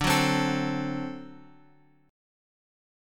D7 chord {x 5 4 5 x 5} chord
D-7th-D-x,5,4,5,x,5.m4a